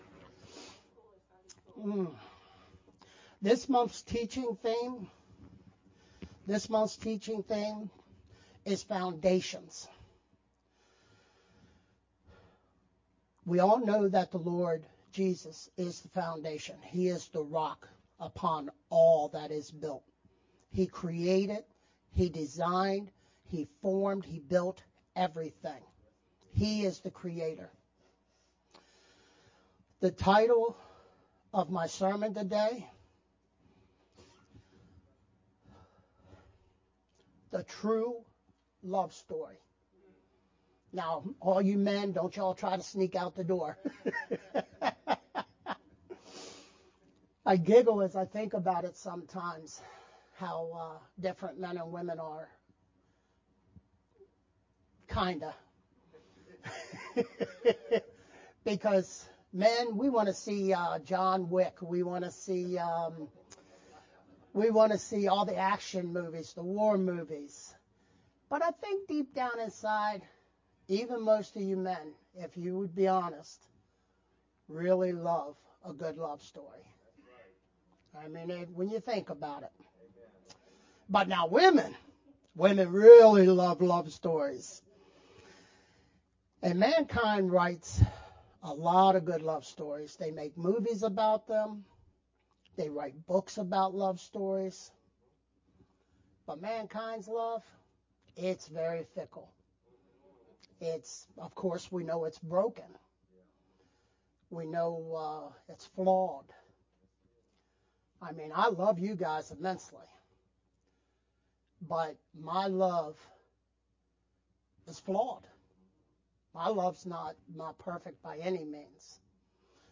VBCC-Sermon-only-Aug-11th_Converted-CD.mp3